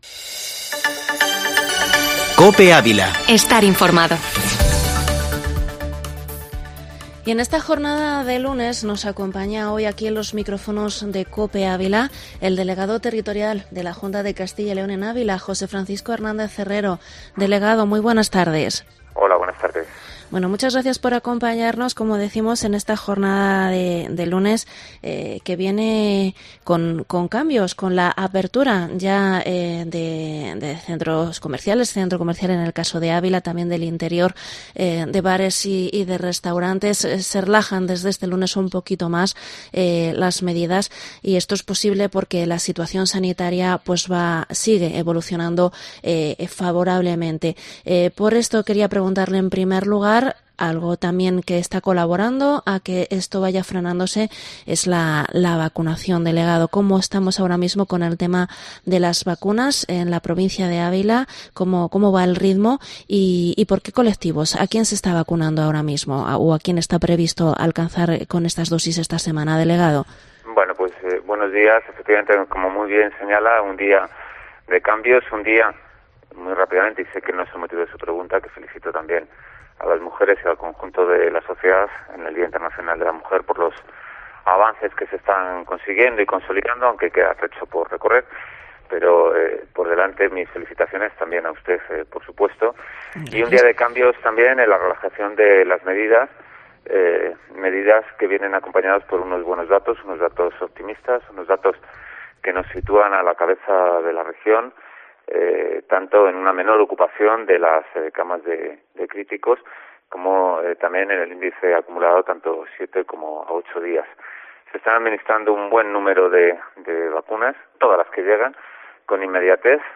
Entrevista delegado territorial Junta CyL 08/03/2021